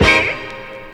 HN_FUNKYHORN.WAV